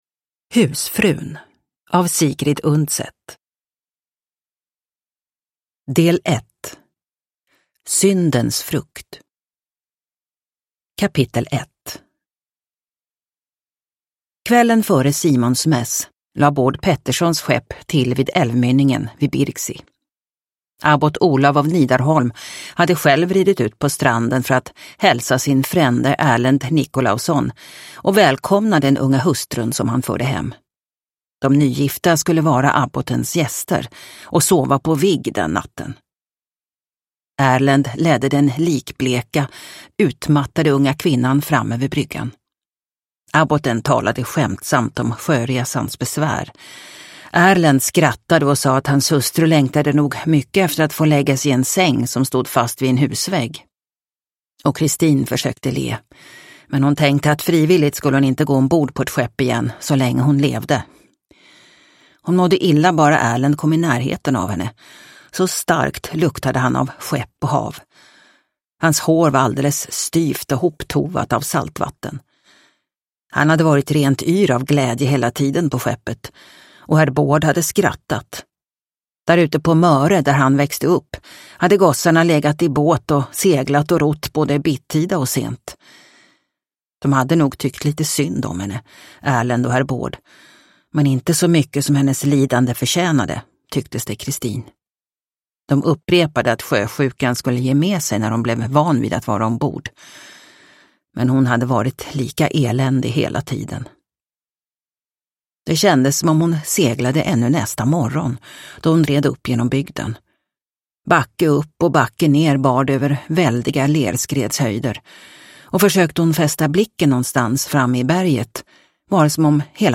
Husfrun – Ljudbok – Laddas ner